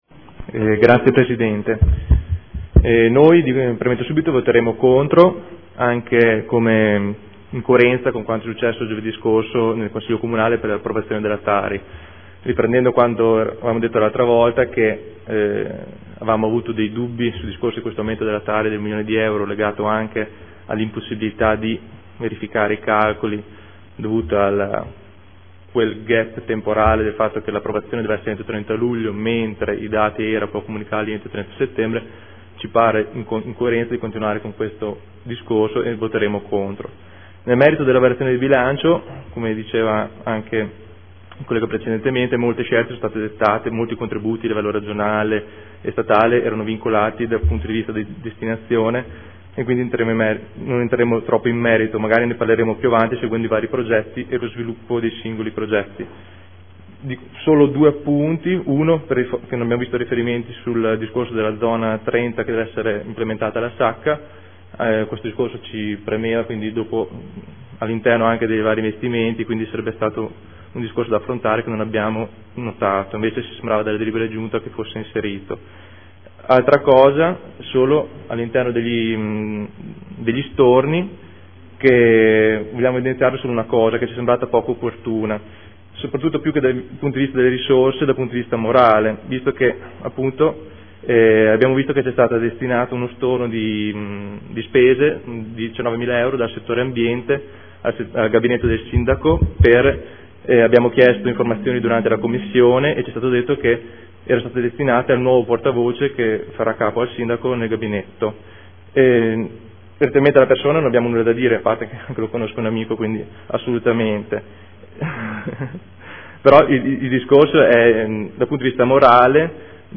Seduta del 31/07/2014. Dichiarazione di voto. Bilancio di Previsione Armonizzato 2014/2016 – Piano Investimenti 2014/2016 – Programma Triennale dei Lavori Pubblici 2014/2016 – Documento Unico di Programmazione 2014/2016 – Variazione di Bilancio n. 2 – Riadozione e conferma degli schemi di Bilancio